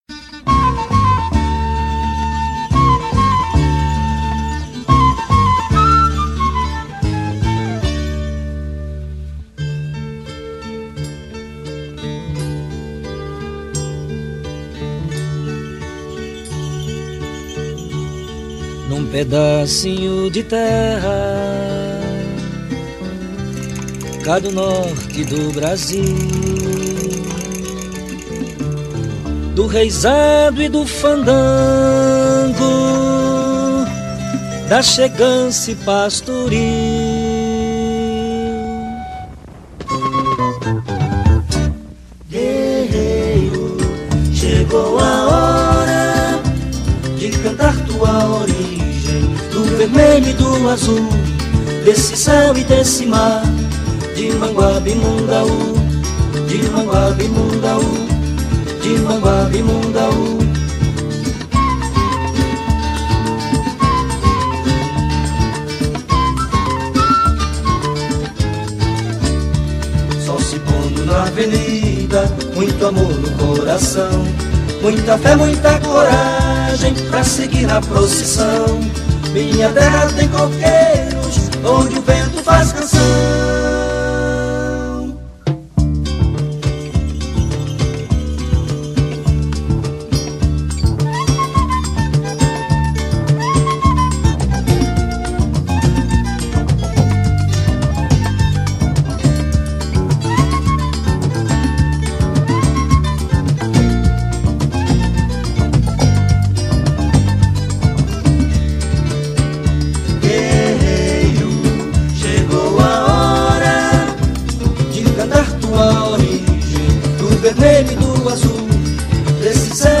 voz e violão
contrabaixo
viola e cavaquinho
flauta